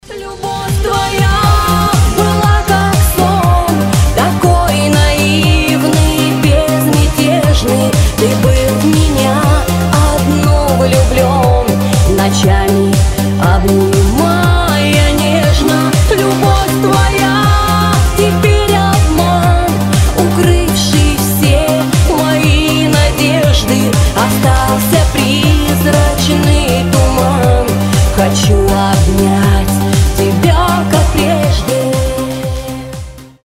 • Качество: 320, Stereo
грустные
женский голос